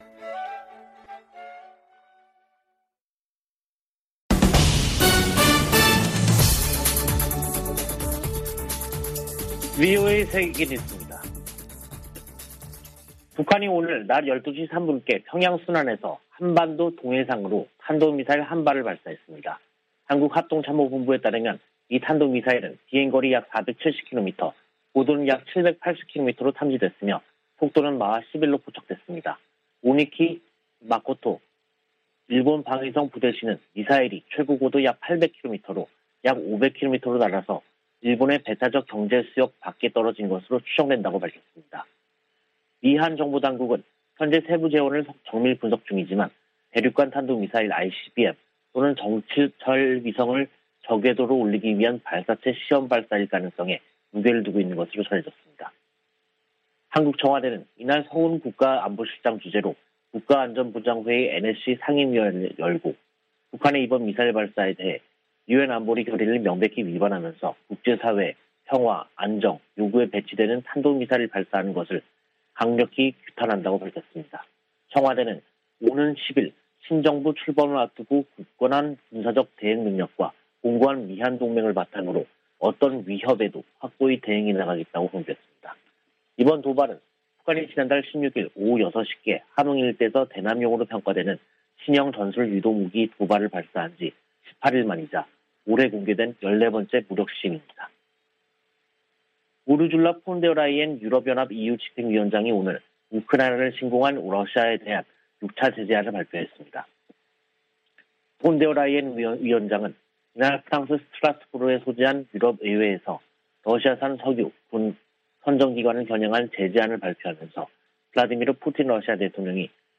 VOA 한국어 간판 뉴스 프로그램 '뉴스 투데이', 2022년 5월 4일 3부 방송입니다. 북한이 한국 새 정부 출범을 앞두고 또 다시 탄도미사일을 발사했습니다. 미국은 북한의 대륙간탄도미사일(ICBM) 도발에 대한 새 유엔 안보리 결의를 위해 논의하고 있다고 밝혔습니다. 세계 여러 나라가 안보리 대북 제재 조치 등을 즉각 수용할 수 있는 법적 체계를 갖추지 못했다는 자금세탁방지기구의 평가가 나왔습니다.